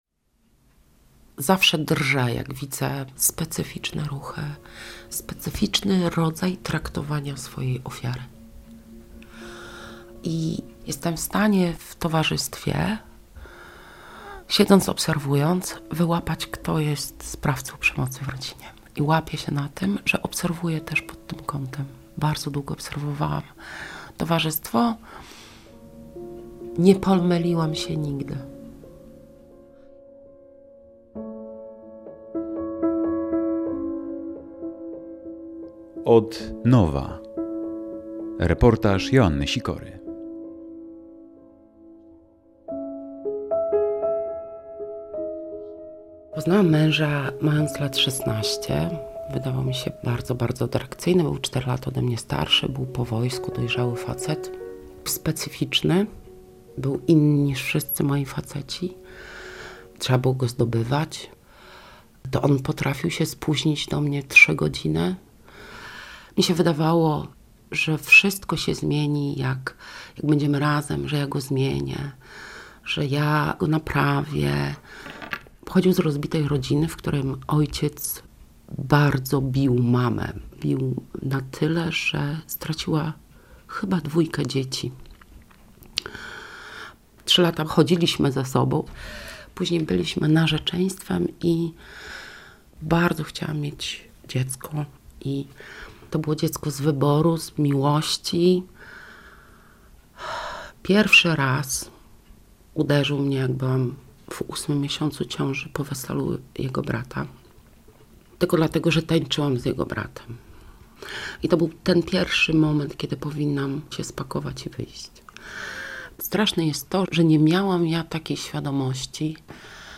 Radio Białystok | Reportaż